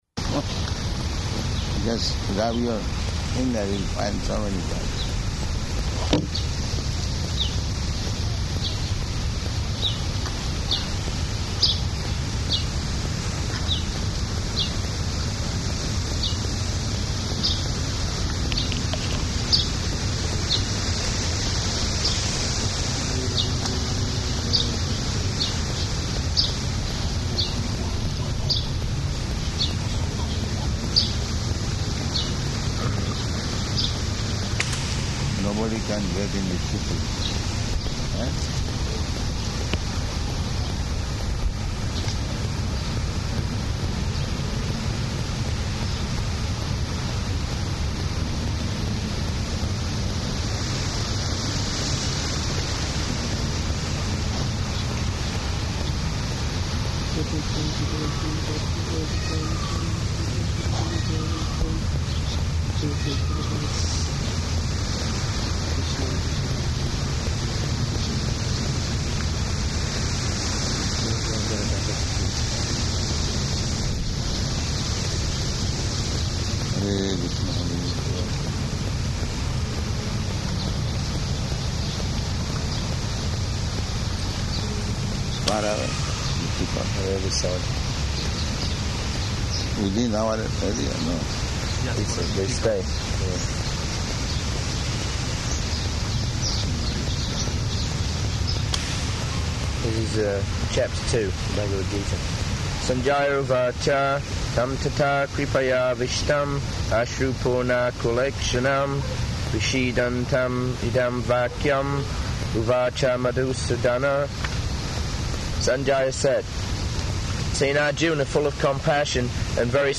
Bhagavad-gītā 2.1--2.12 Reading
Bhagavad-gītā 2.1--2.12 Reading --:-- --:-- Type: Bhagavad-gita Dated: June 15th 1976 Location: Detroit Audio file: 760615BG.DET.mp3 [Rain falling throughout; sitting under the trees on the river bank] Prabhupāda: Just rub your finger, you'll find so many germs.
[ japa ] [pause] [peacocks calling] [break] Prabhupāda: ...far away the peacock?